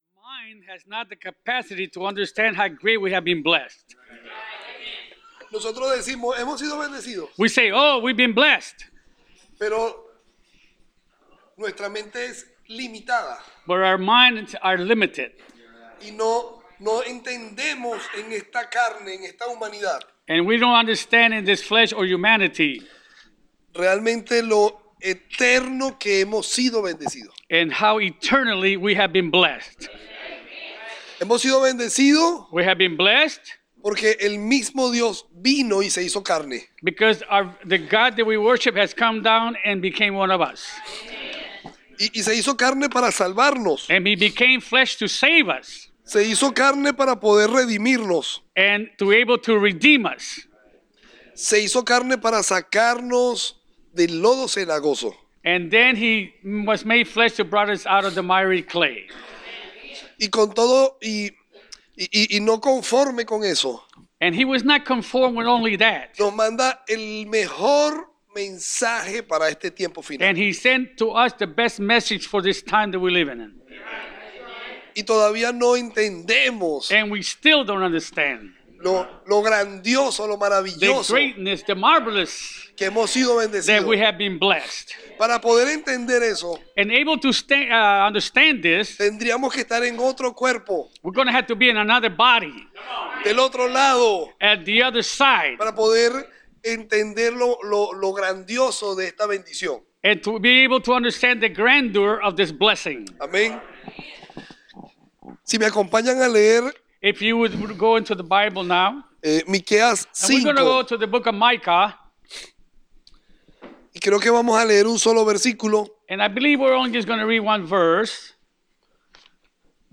Preached January 1, 2023